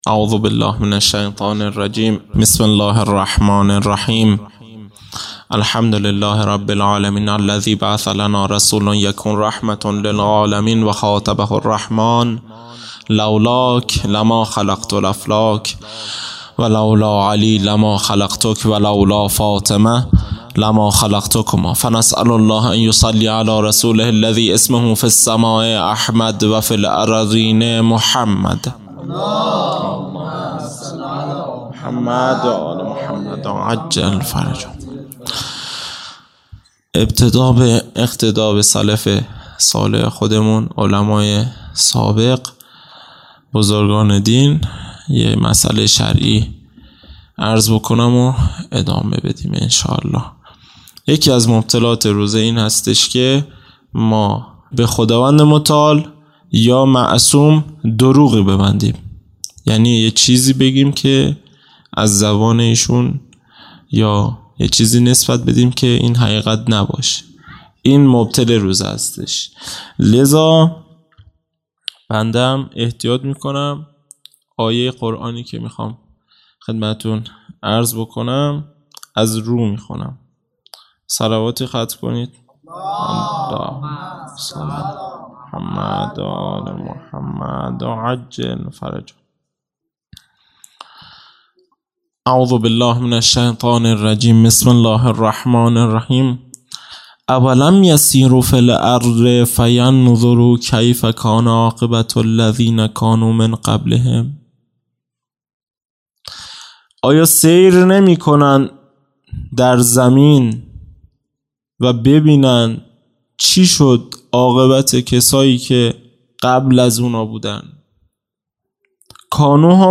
خیمه گاه - هیئت بچه های فاطمه (س) - سخنرانی
جلسۀ هفتگی 9 خرداد 1398 (به مناسبت وداع با ماه مبارک رمضان)